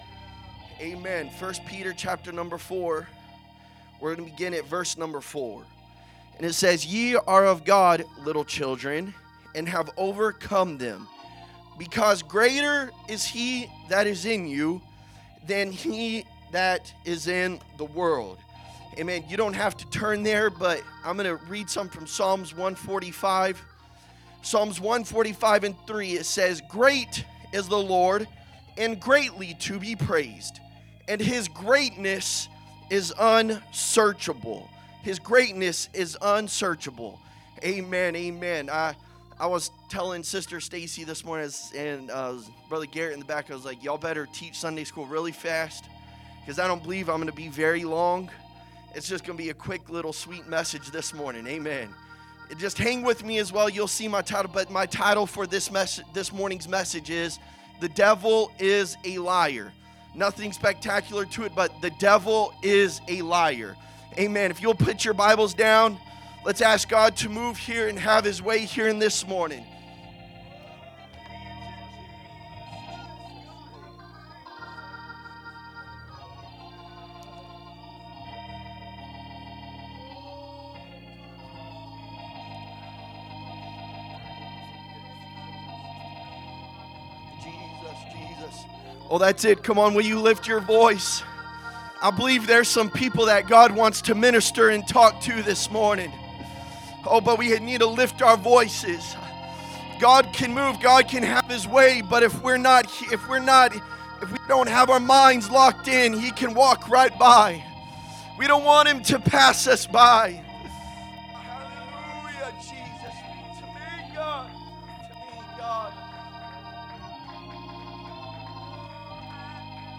Sunday Morning Message